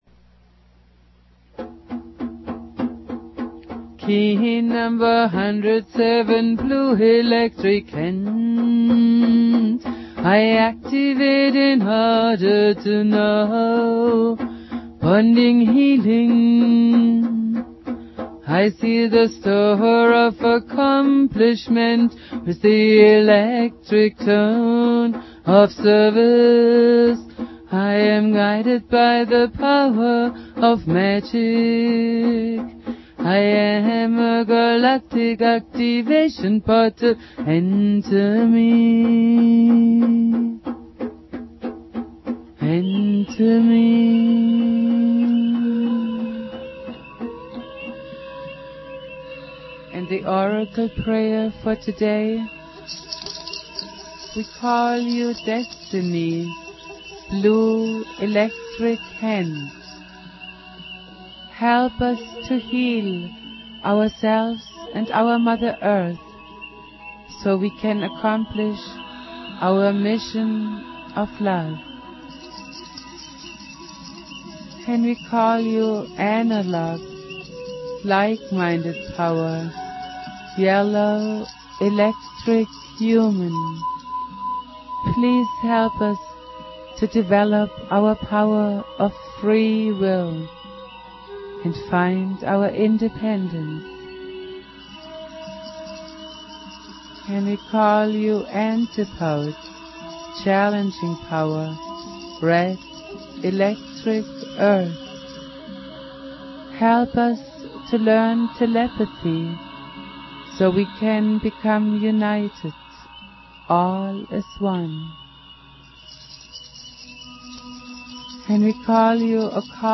Valum Votan playing flute.
Prayer
Jose Argüelles playing flute
produced at High Flowing Recording Studio